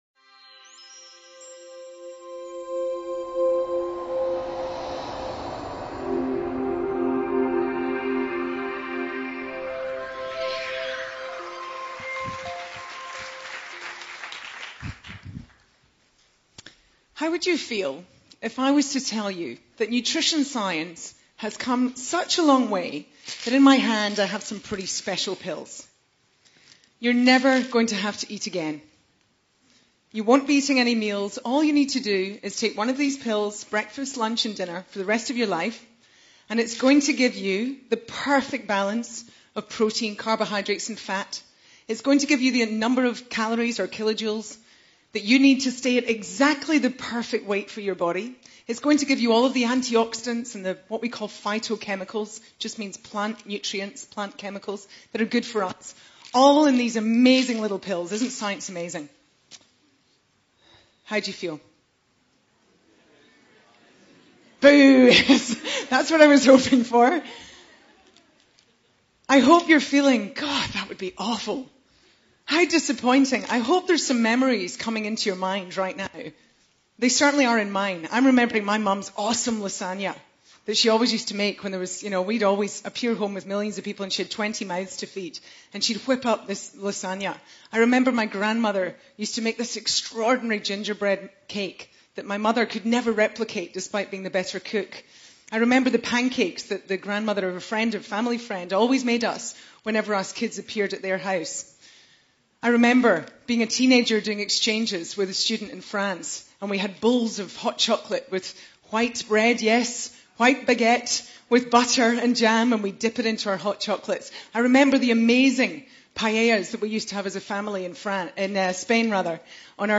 TEDx Talk